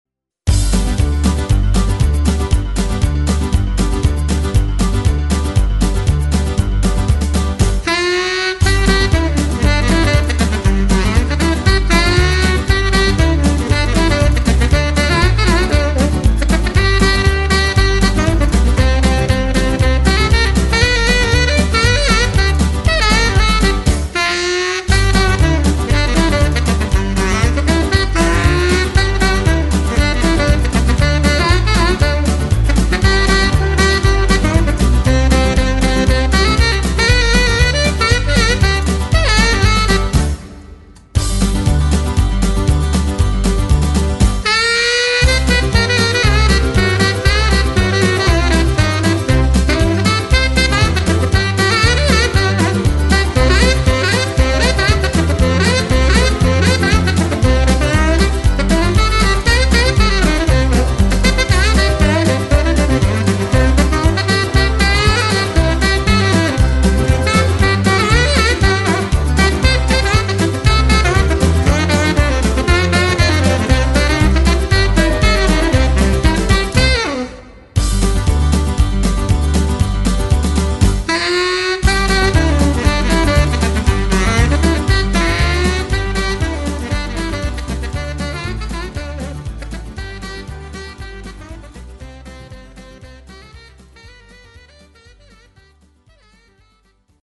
SOME HOT DIXIELAND STUFF